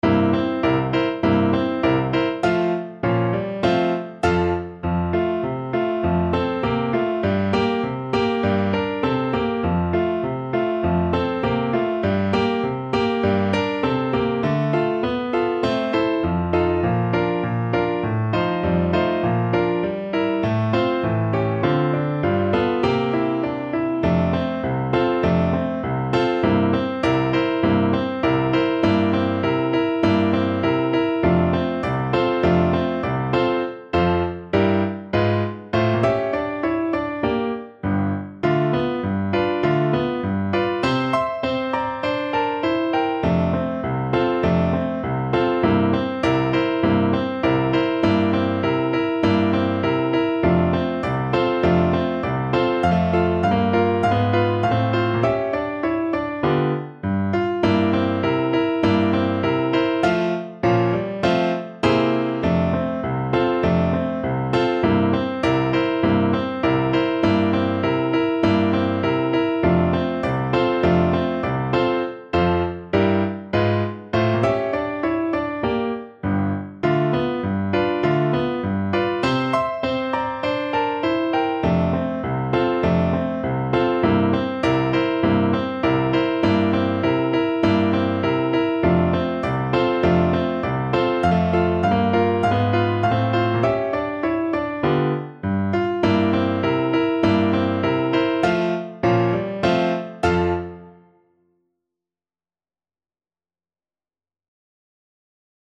2/2 (View more 2/2 Music)
Moderato =c.100